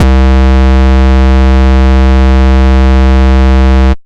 Kick Saw.wav